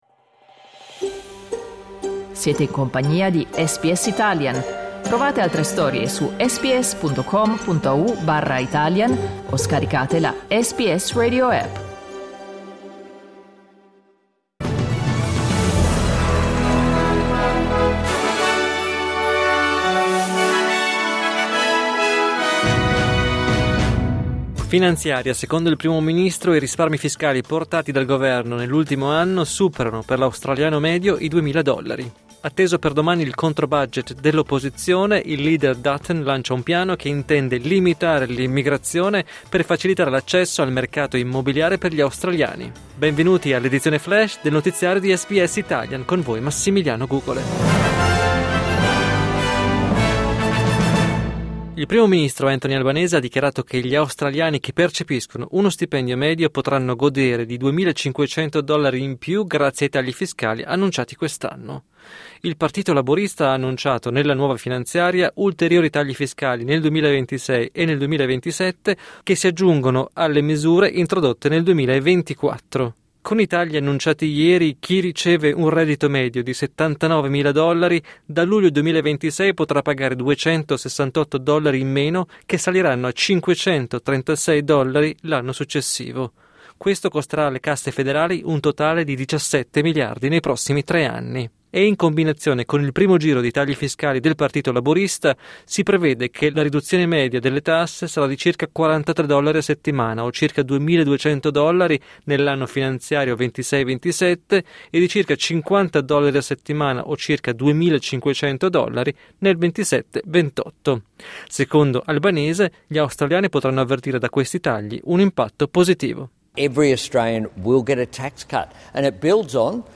News flash mercoledì 26 marzo 2025